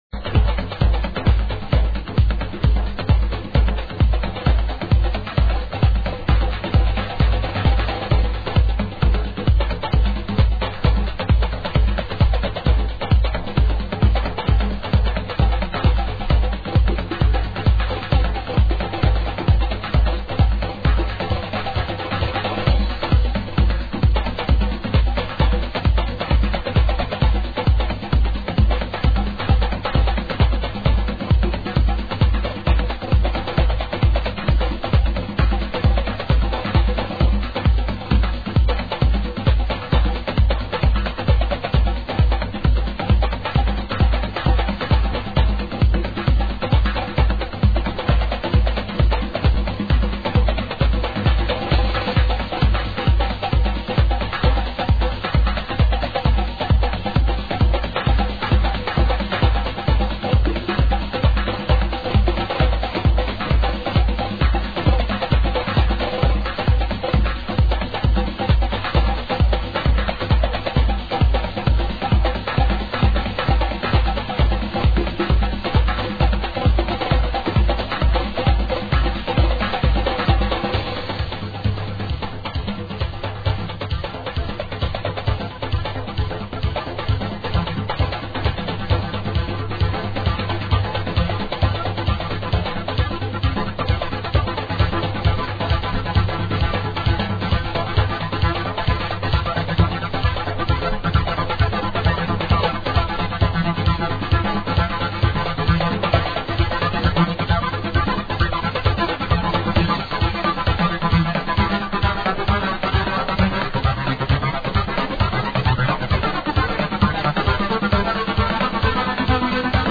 Mainly because the quality sucks...